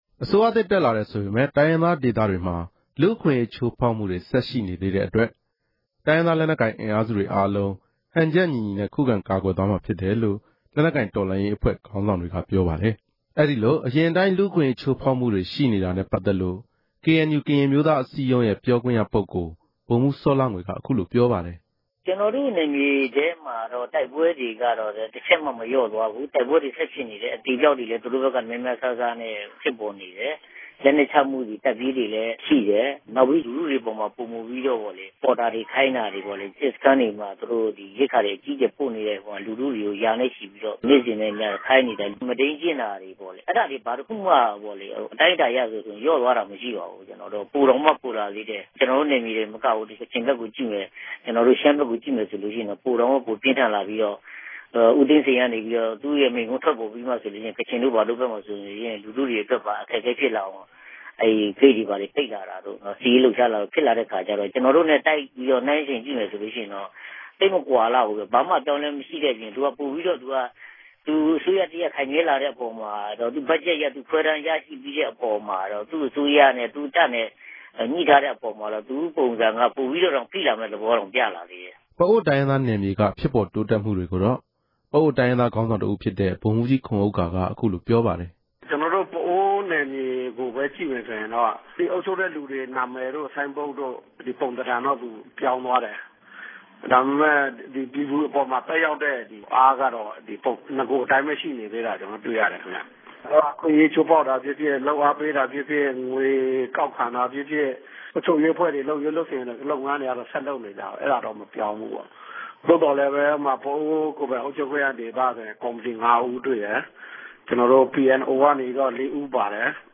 ကိုယ့်အိမ် ကိုယ်ရွာကို ပြန်ချင်ပေမယ့် မပြန်ရဲကြတဲ့အကြောင်းကို ကရင်ပြည်နယ် ဒေသခံတဦးက ပြောပြပါတယ်၊၊
စုစည်းတင်ပြချက်။